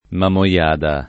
Mamoiada [ mamo L# da ]